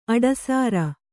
♪ aḍasāra